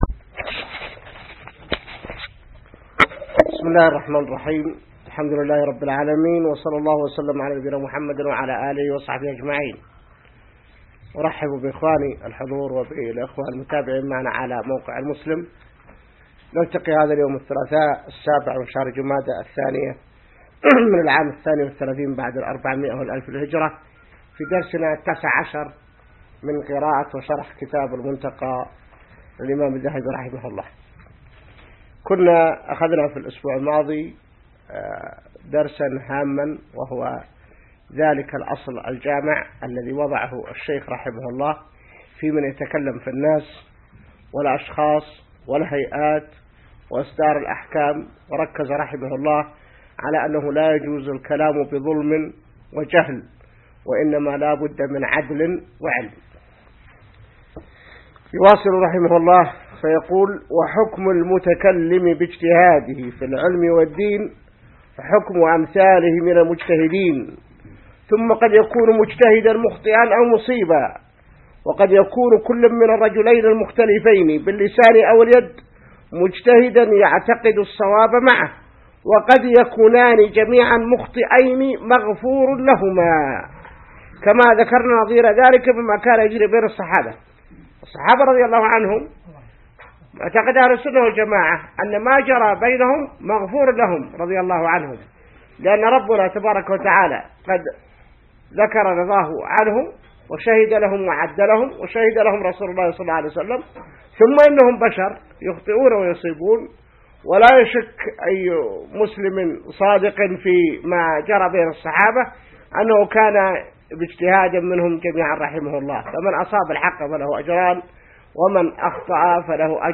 الدرس 19 من شرح كتاب المنتقى | موقع المسلم